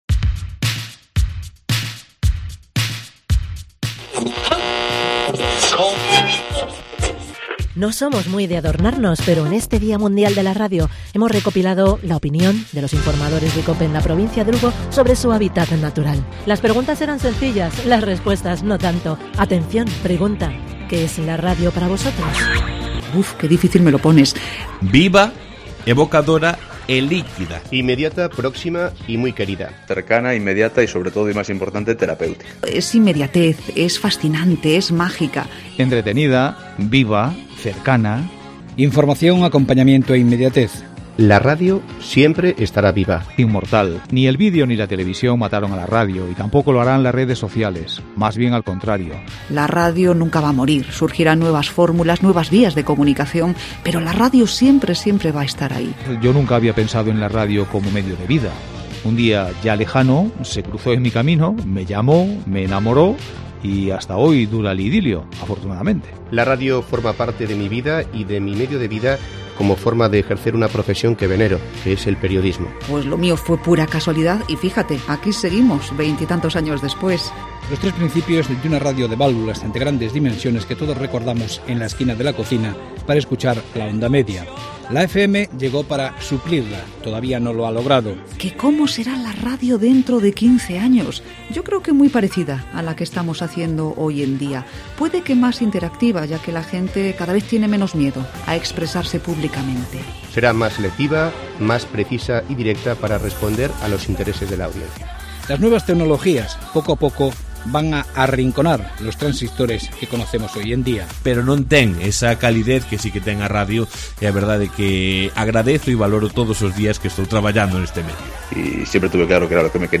Los comunicadores de Cope en nuestra provincia -con muchos decenios atesorados al frente de un micro- dan su particular visión del significado y esencia del trabajo en las ondas, en este Día Mundial de la Radio.
Las palabras inmediatez e innmortal se suceden en bastantes ocasiones en este pequeño montaje sonoro...